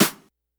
Snares
snr_24.wav